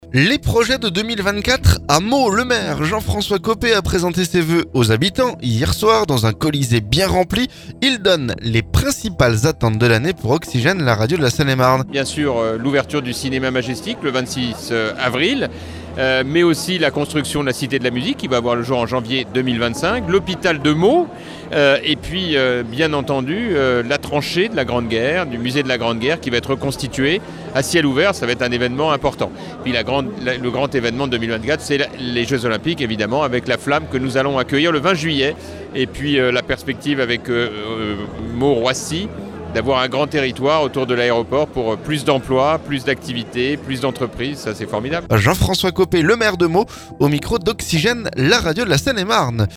Il donne les principales attentes de l'année pour Oxygène, la radio de la Seine-et-Marne.